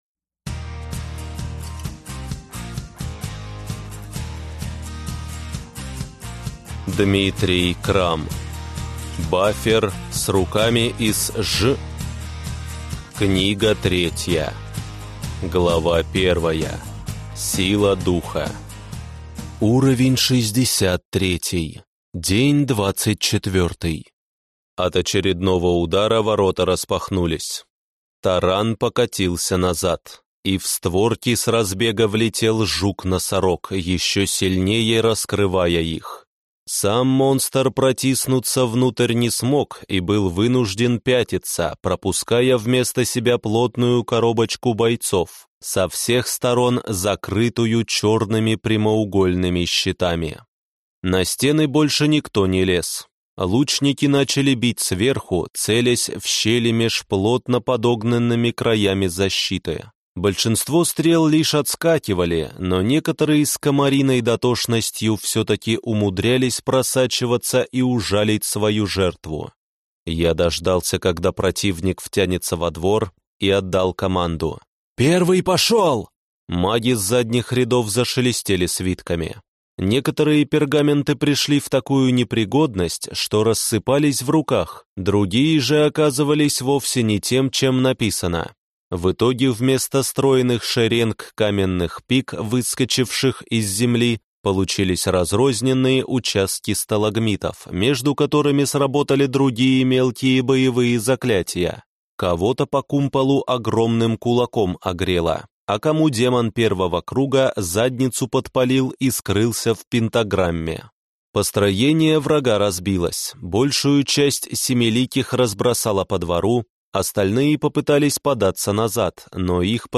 Аудиокнига Бафер с руками из ж… Книга 3 | Библиотека аудиокниг